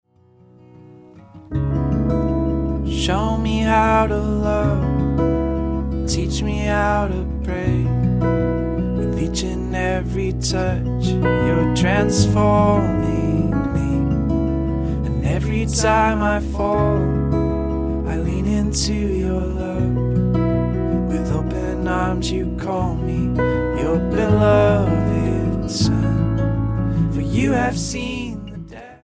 STYLE: Ambient/Meditational
is in a suitably sombre mood
the formula of guitar and keys continues